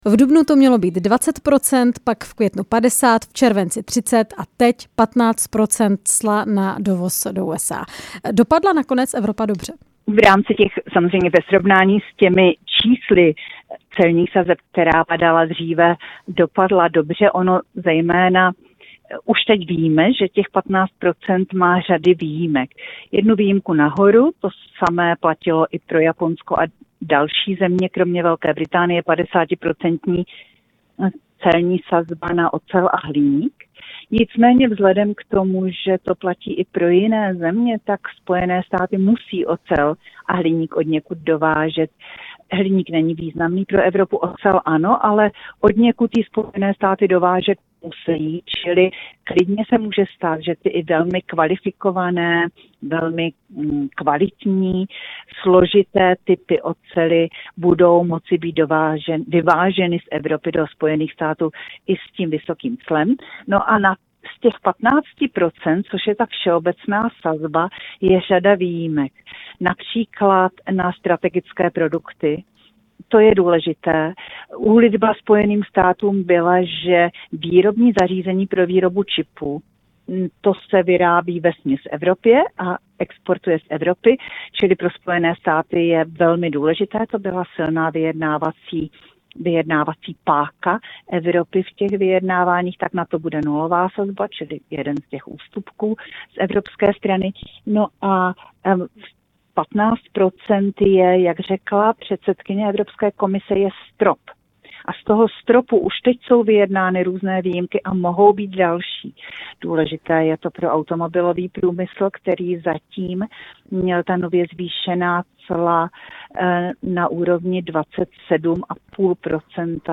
Rozhovor s ekonomkou